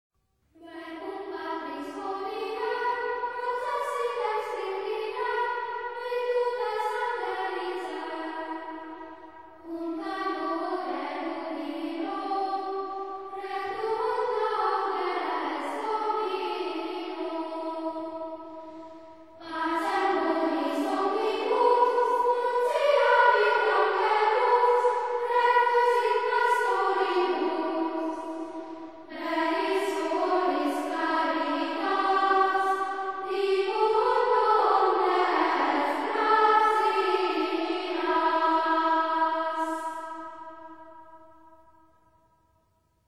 Григорианские хоралы из Градуала монастыря Сен-Ирьи (Gradual of Saint Yrieix) в Лимузене.